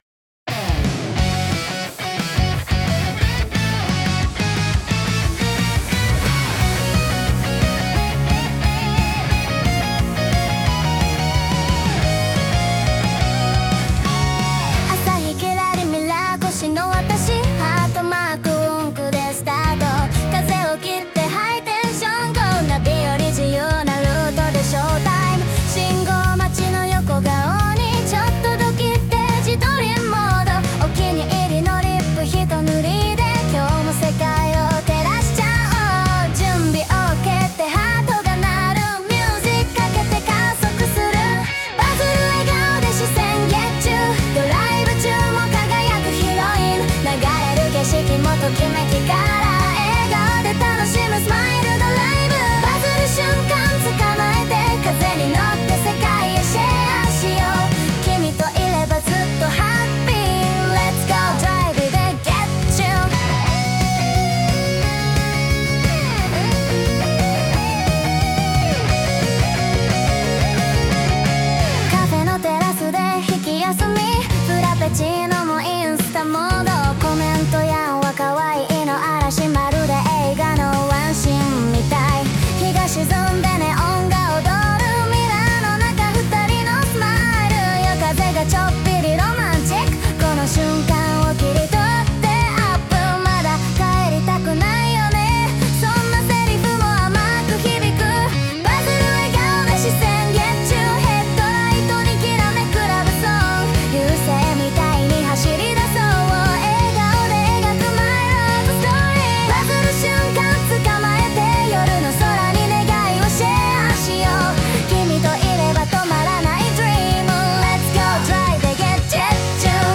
元気いっぱいのドライブソングで気分をリフレッシュ♪